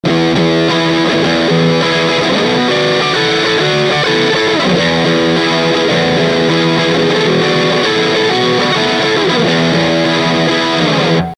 Guitar Fender STRTOCASTER
Amplifier VOX AD30VT AC30TB
次は「STYLE」をアリキックにセットした音です。